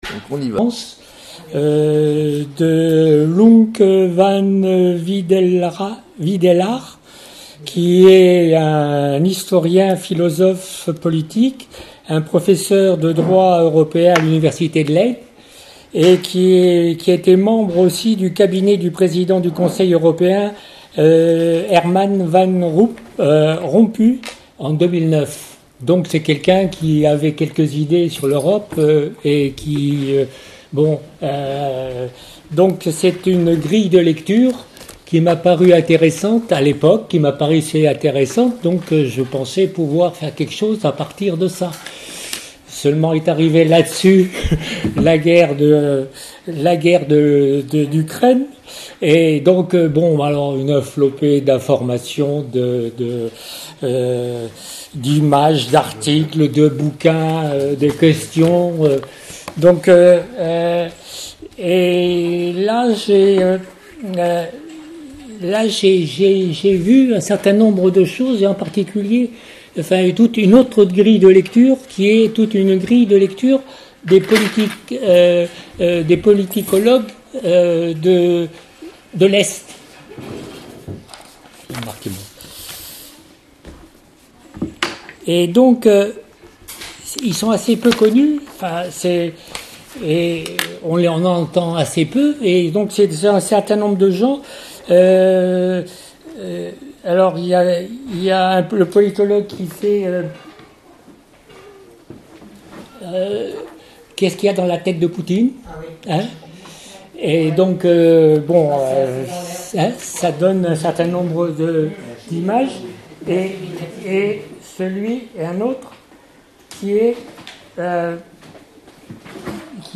Enregistrement audio exposé